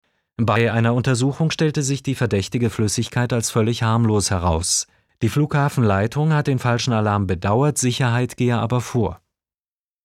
Die Nachricht: verständliche Radionachrichten
• In P2 dagegen erscheint die Gliederung unsystematisch. Die Sprecheinheiten sind relativ groß, oft doppelt so lang wie in P1. Es gibt also selten Sprechpausen. In einer Sprecheinheit werden grundsätzlich zu viele Informationen akzentuiert und meist automatisch das letzte Wort, unabhängig davon, ob es sich um eine neue Information handelt oder nicht. Dadurch ist die wirkliche Neuigkeit in einer Sprecheinheit für Hörer schwer erkennbar.
Meldung 3: N1.P2 – einfach geschriebener Text, nicht-sinnvermittelnd gesprochen